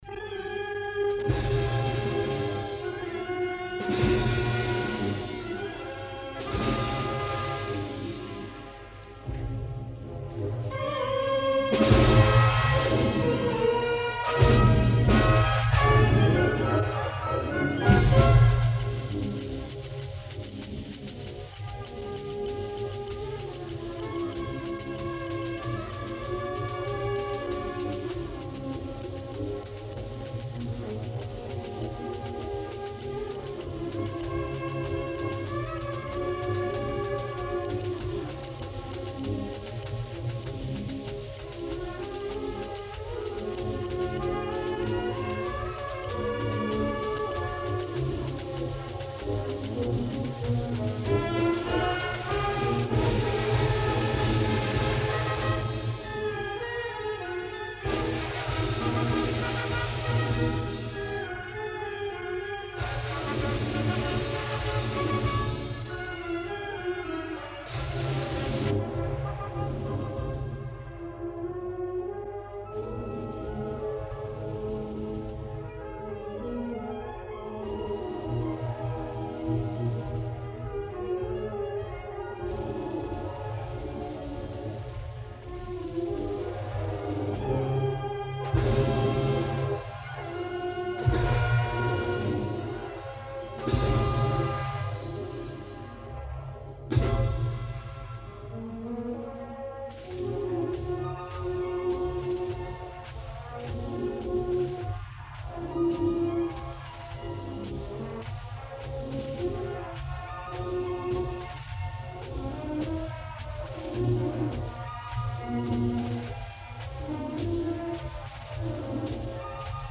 Concert de Sa Fira a l'Esglèsia de la Nostra Senyora de la Consolació